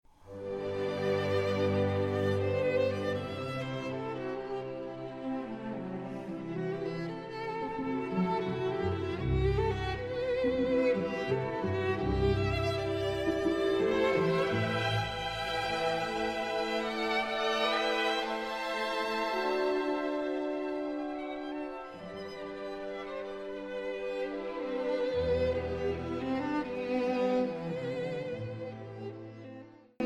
Likeable debut recording from a promising young violinist
violin
A likeable debut disc, well recorded.